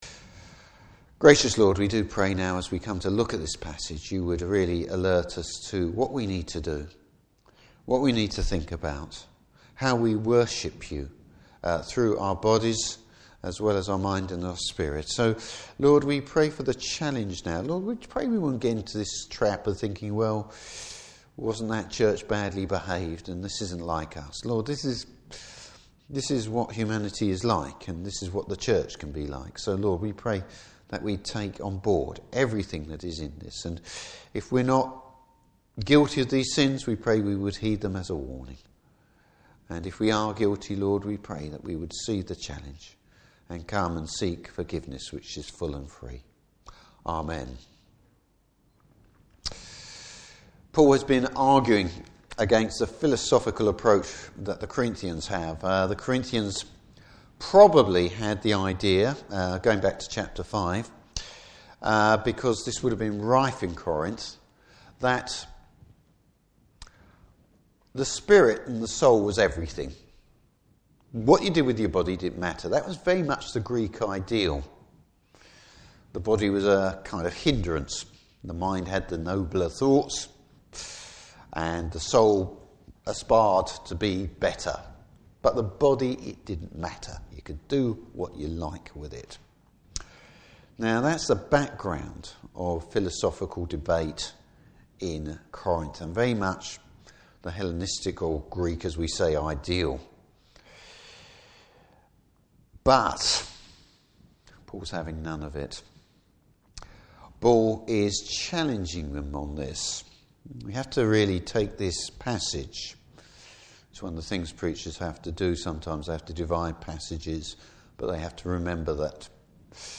Service Type: Morning Service What it means to be a Temple of the Holy Spirit.